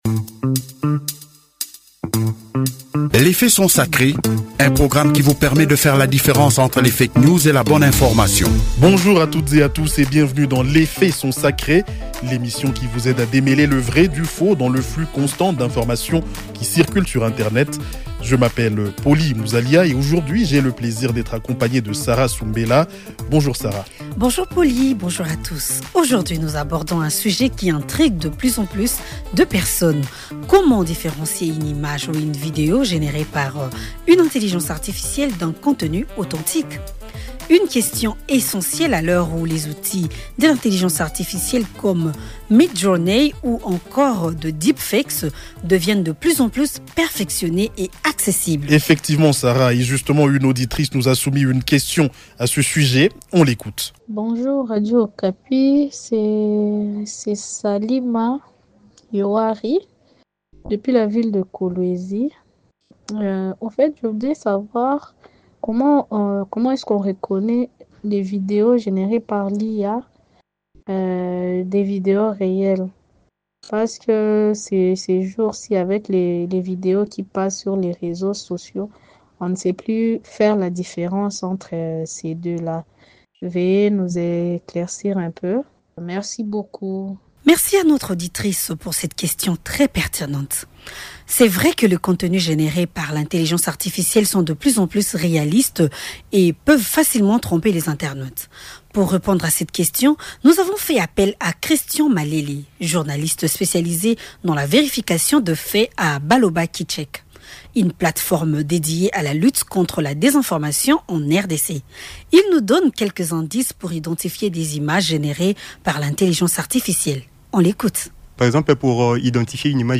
Nous avons posé la question